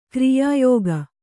♪ kriyā yōga